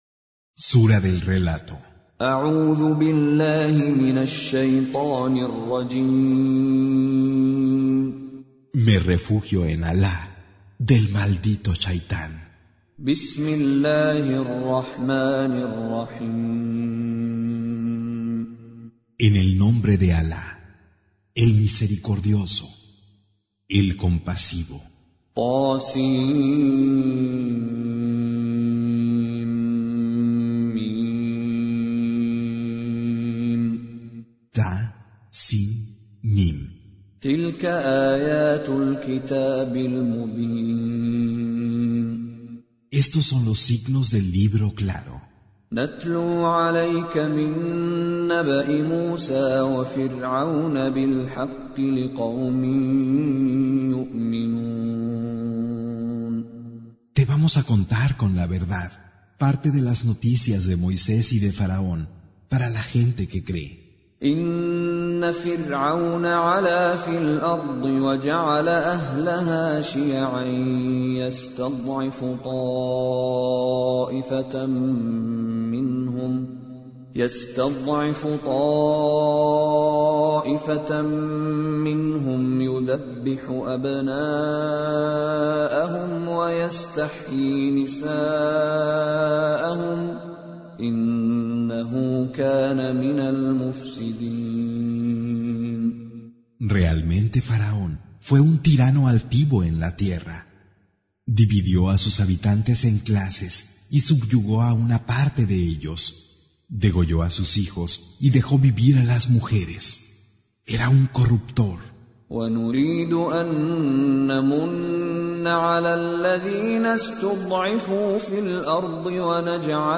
Recitation
Con Reciter Mishary Alafasi
Surah Sequence تتابع السورة Download Surah حمّل السورة Reciting Mutarjamah Translation Audio for 28. Surah Al-Qasas سورة القصص N.B *Surah Includes Al-Basmalah Reciters Sequents تتابع التلاوات Reciters Repeats تكرار التلاوات